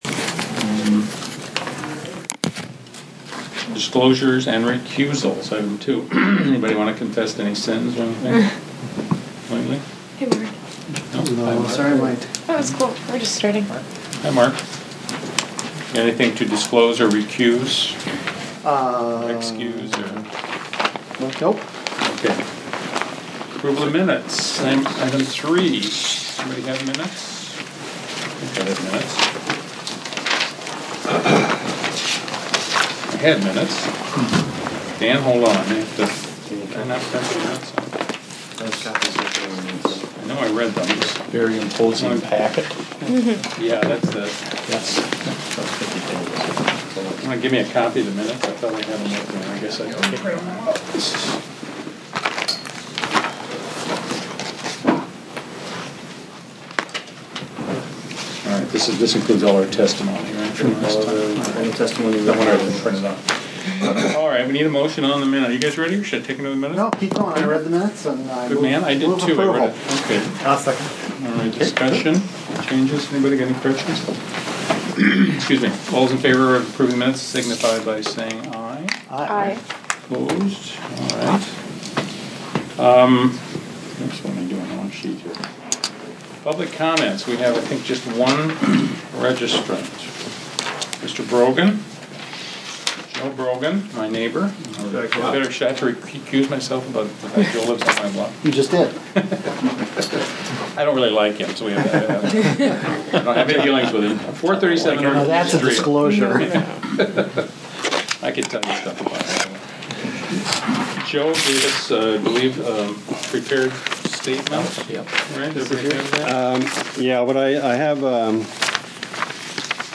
James Madison Park Surplus Property Committee meeting, January 13 2011
I was also able to record this meeting.